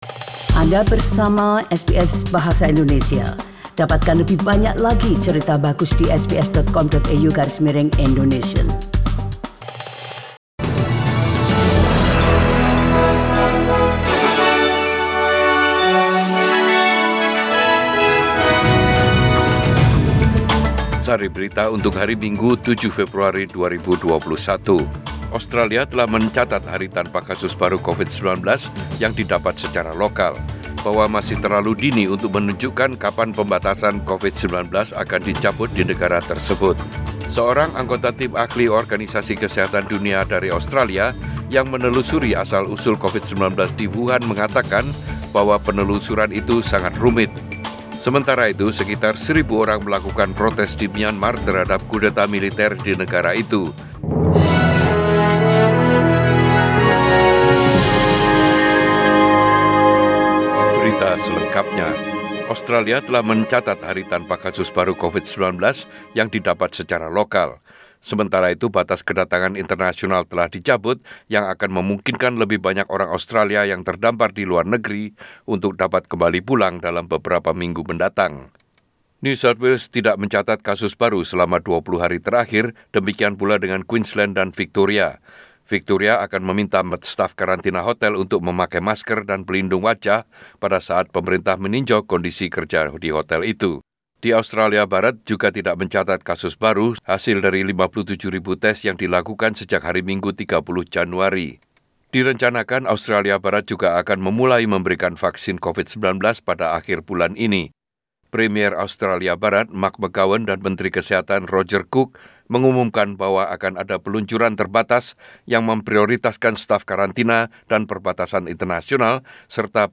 SBS Radio News in Bahasa Indonesian - 7 February 2021